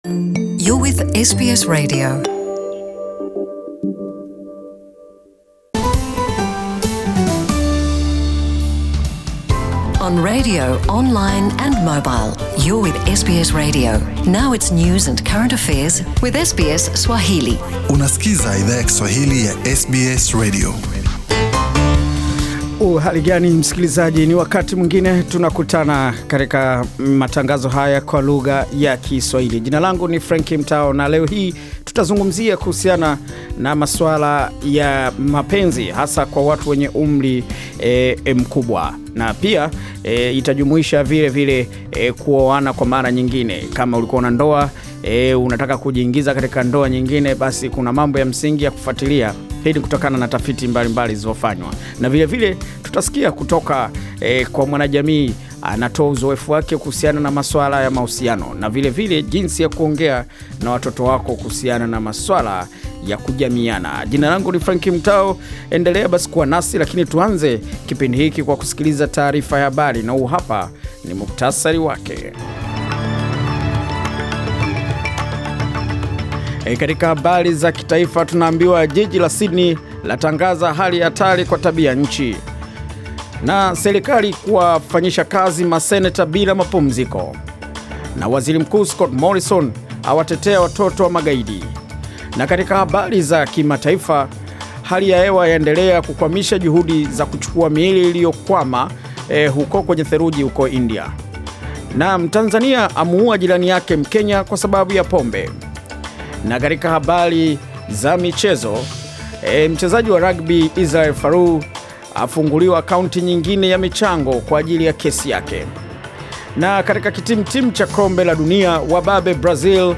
Taarifa ya Habari
SBS World News Bulletin Source: SBS